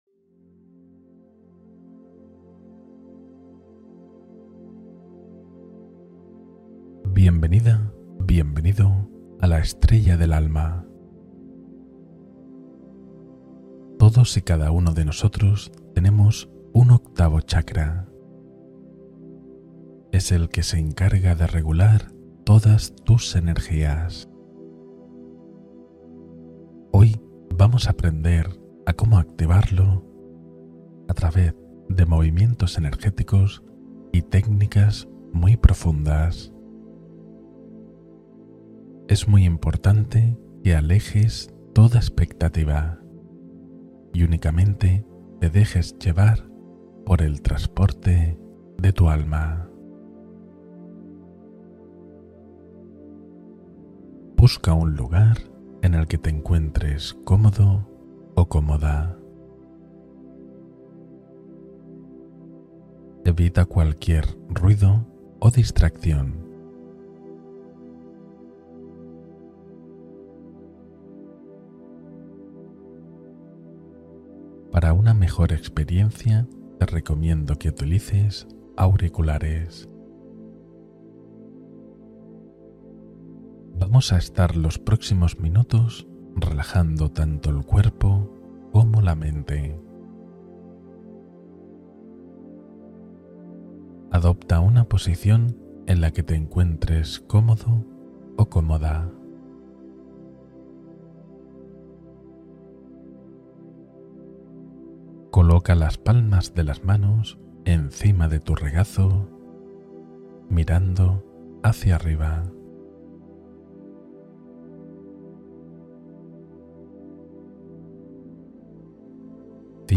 Activación de la Estrella del Alma: Meditación de Alineación Energética